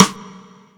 taiko-normal-hitclap.wav